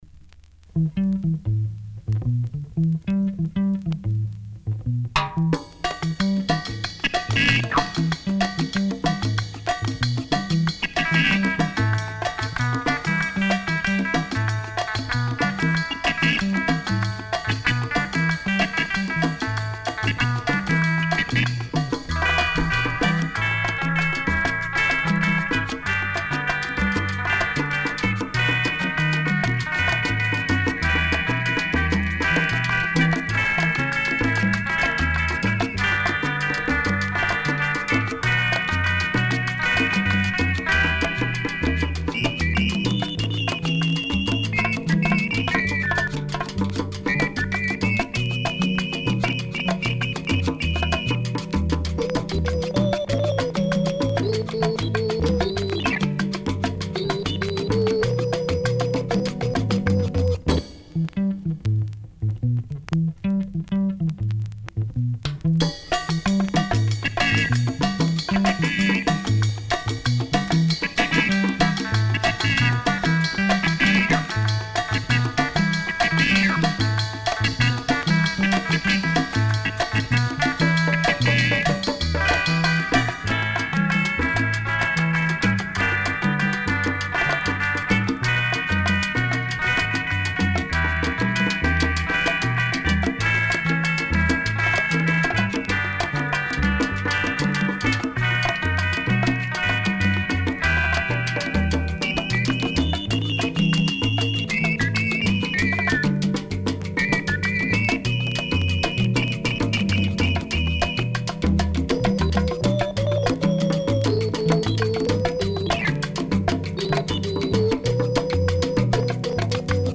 Super deep chicha with a touch of psychedelic rhythms.